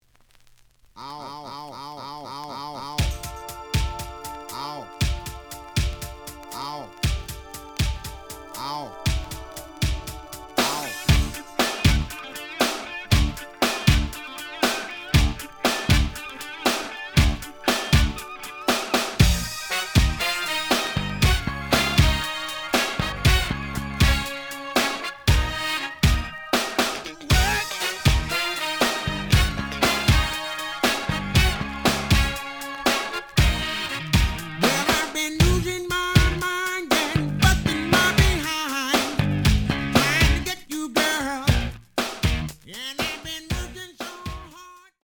試聴は実際のレコードから録音しています。
The audio sample is recorded from the actual item.
●Genre: Funk, 80's / 90's Funk